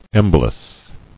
[em·bo·lus]